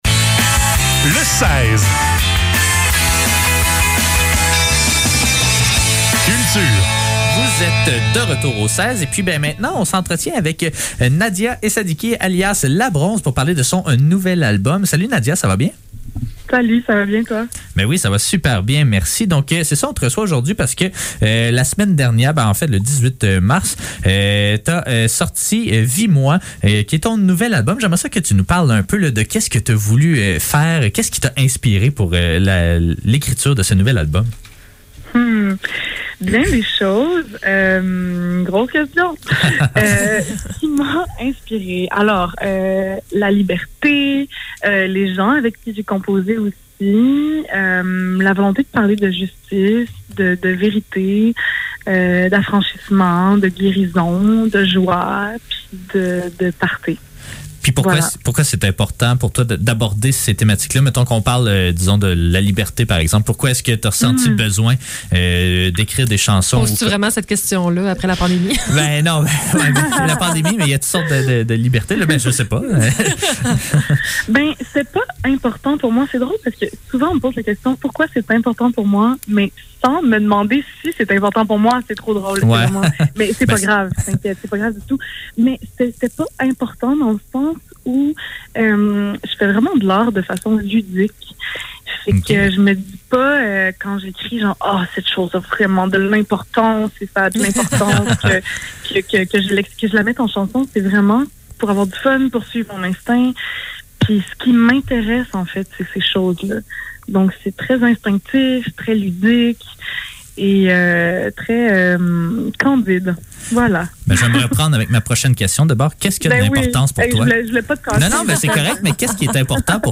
Le seize - Entrevue avec La Bronze - 28 mars 2022
Entrevue-avec-La-Bronze.mp3